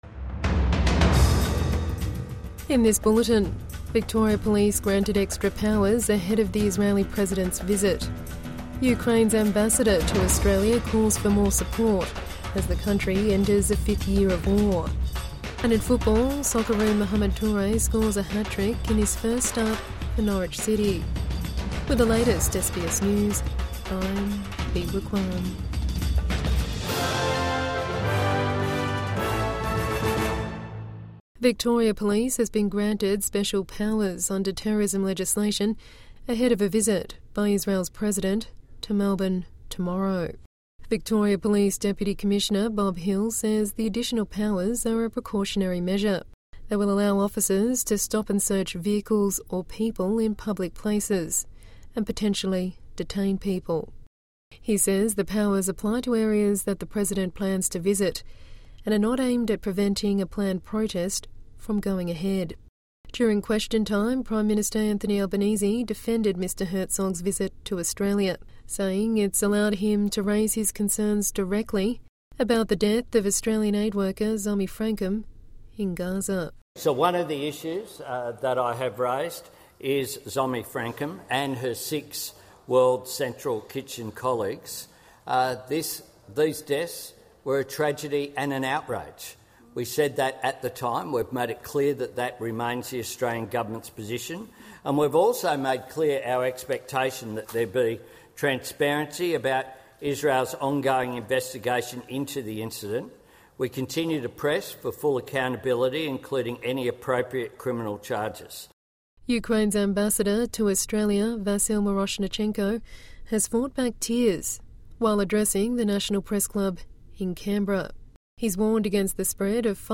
Victoria Police granted extra powers ahead of Herzog visit | Evening News Bulletin 11 February 2026